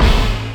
Hits